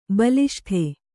♪ baliṣṭhe